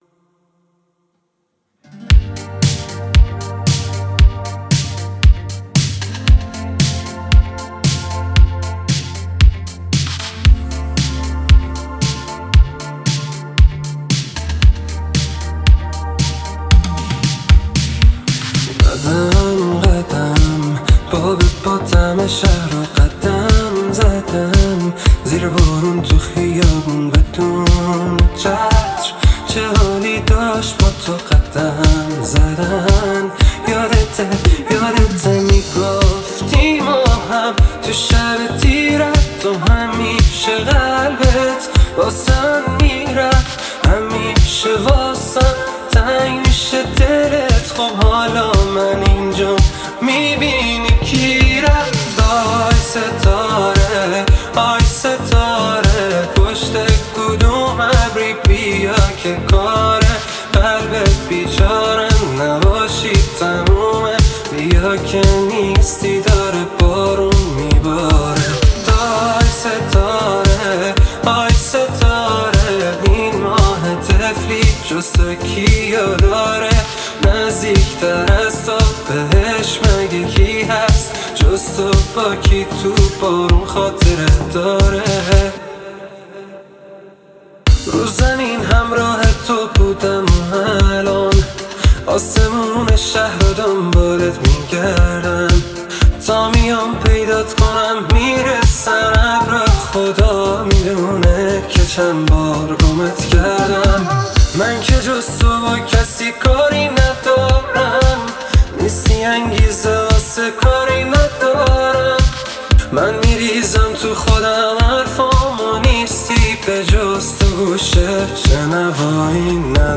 پاپ
اهنگ ایرانی